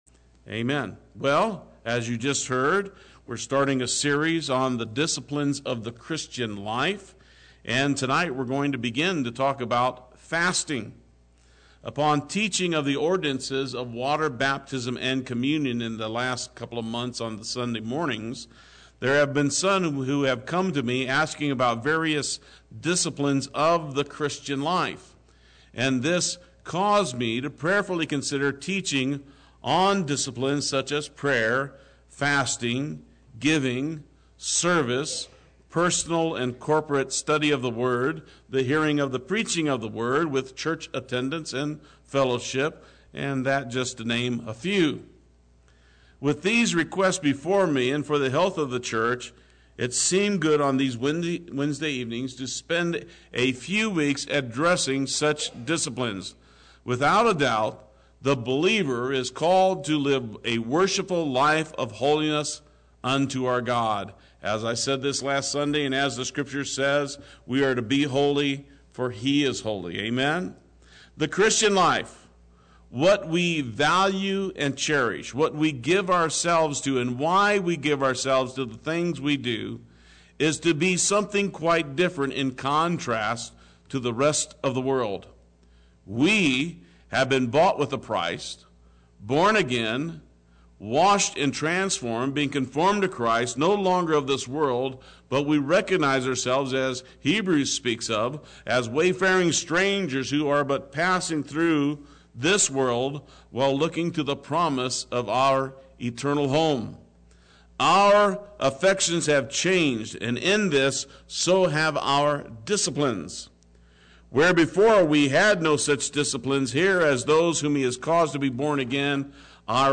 Play Sermon Get HCF Teaching Automatically.
Fasting Wednesday Worship